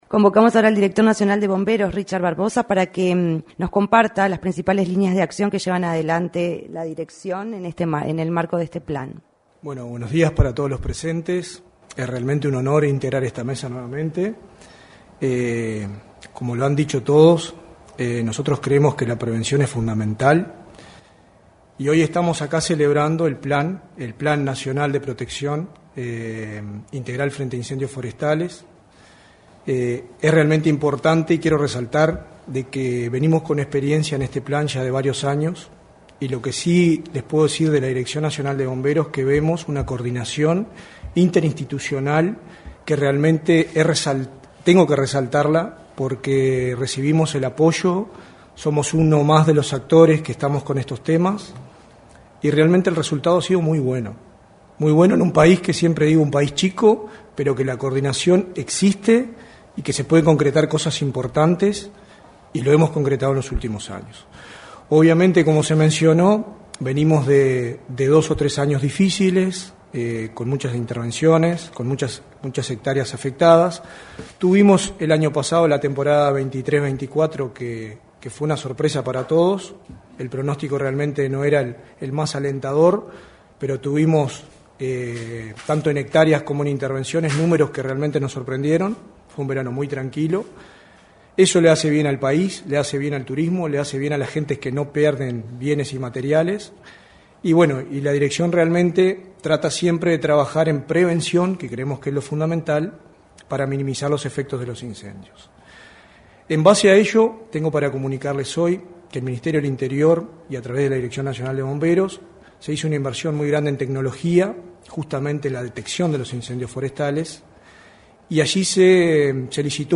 Declaraciones a la prensa de autoridades de Bomberos y Sinae